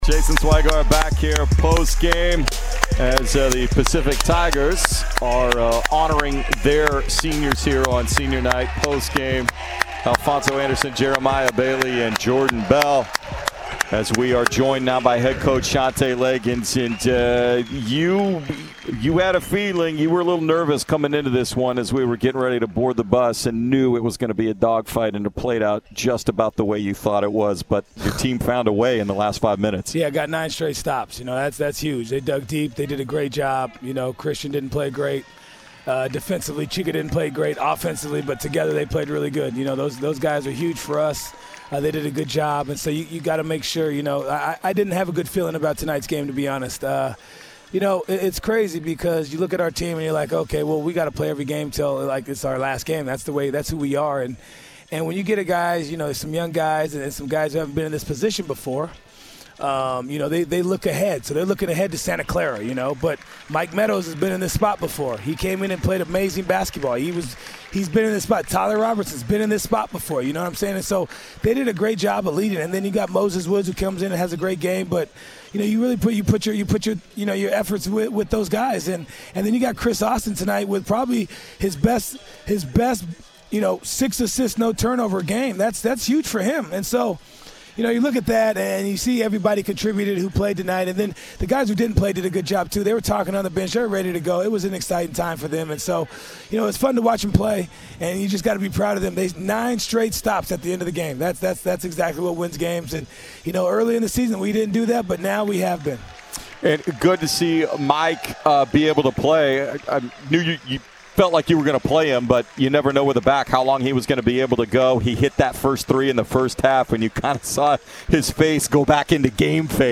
Men's Basketball Radio Interviews